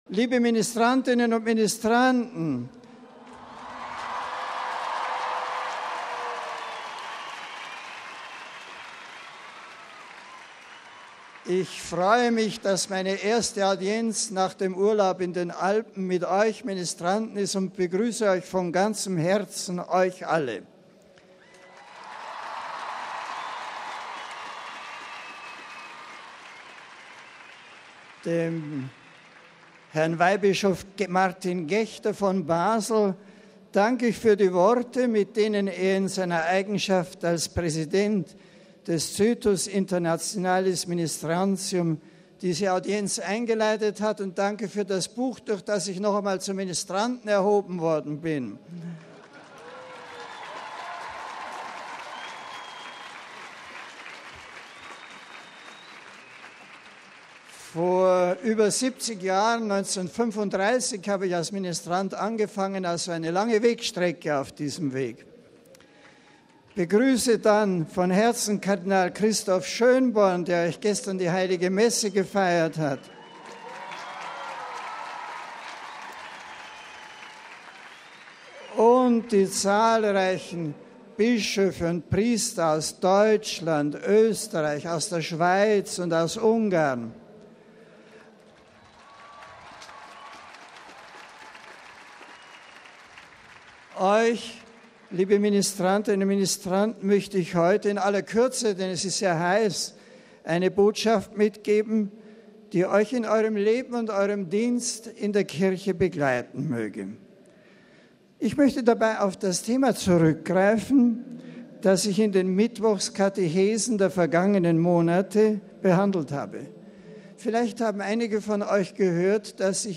MP3 Zehntausende von Ministranten aus ganz Europa haben heute auf dem Petersplatz in Festlaune an der Generalaudienz von Papst Benedikt XVI. teilgenommen.